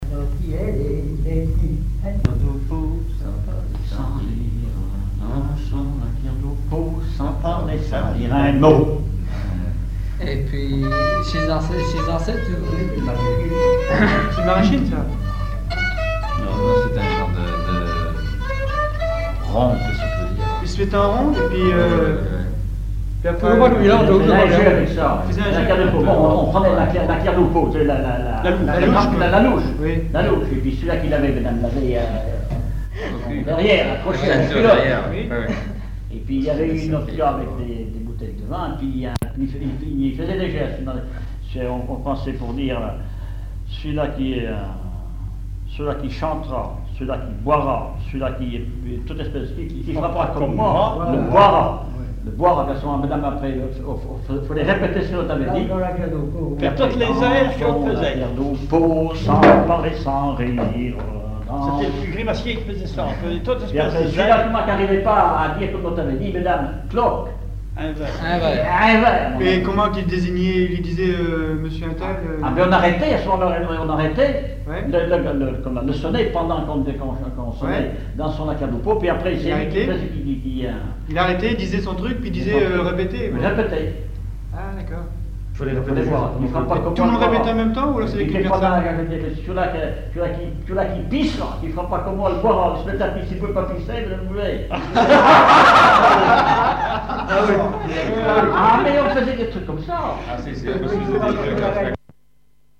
Aiguillon-sur-Vie (L') ( Plus d'informations sur Wikipedia ) Vendée
danse-jeu : trompeuse
chansons populaires et instrumentaux